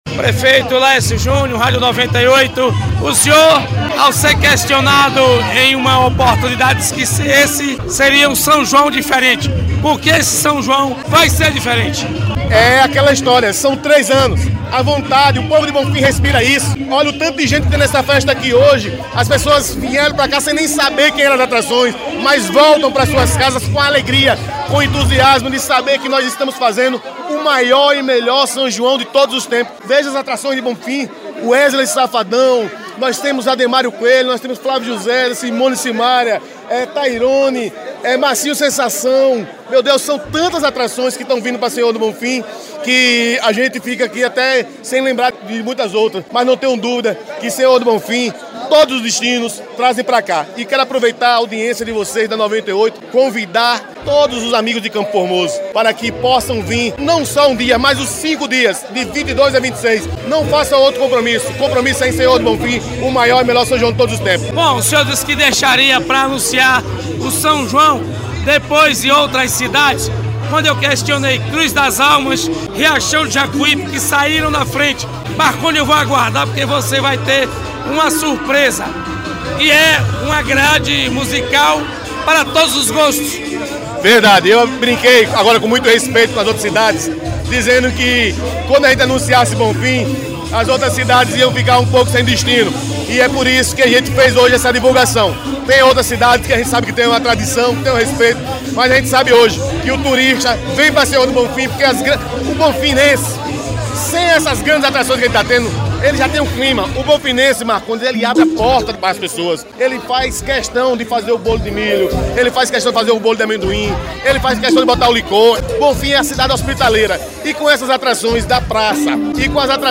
Reportagem: lançamento dos festejos de São João de Sr. do Bonfim